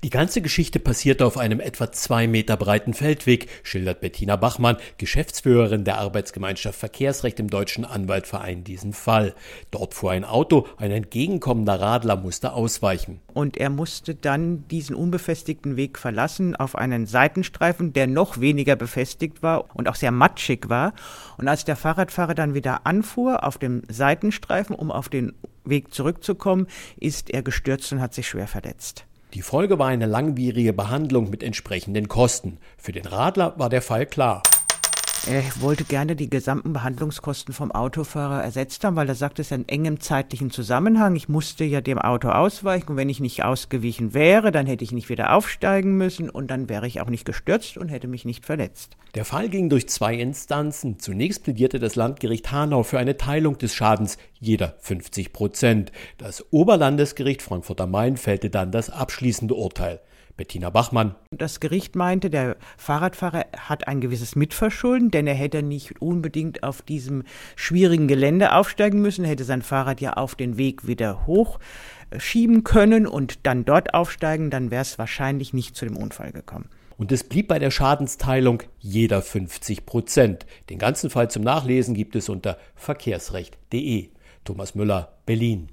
Auto, DAV, O-Töne / Radiobeiträge, Ratgeber, Recht, , , , , , ,